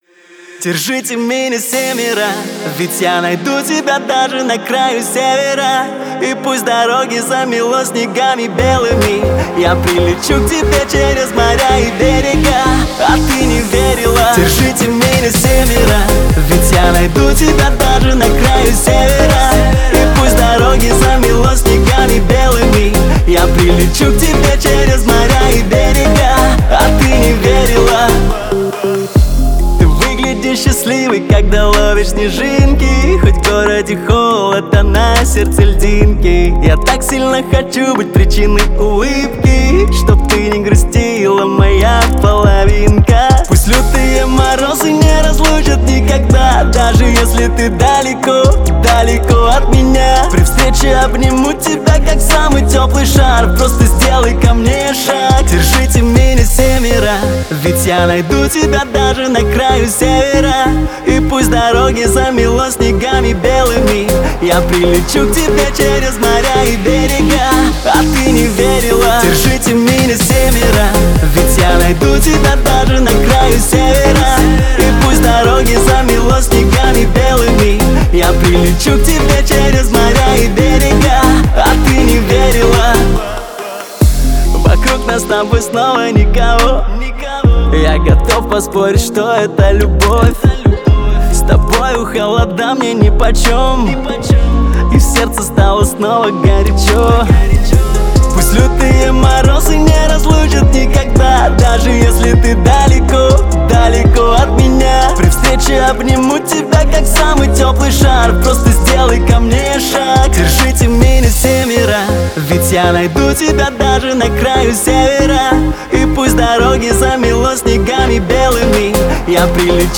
Танцевальная музыка
dance песни